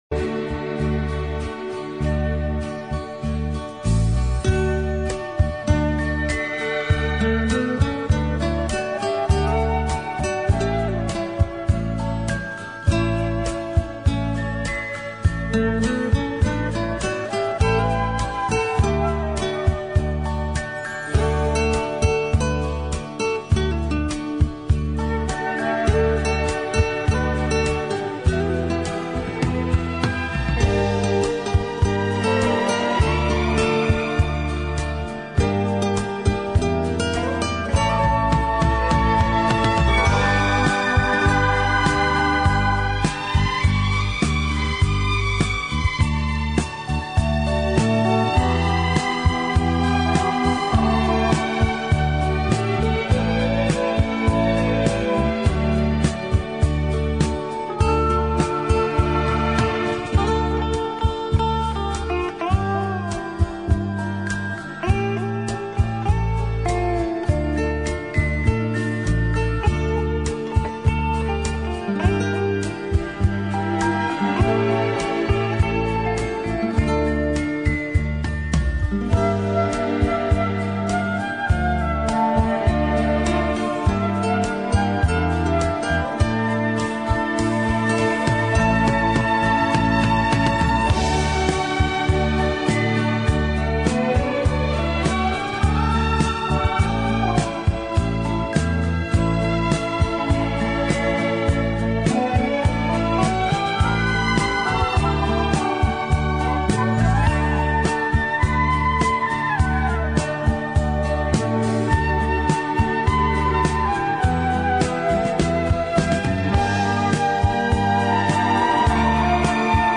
Genre:Pop
Style:Music Hall, Schlager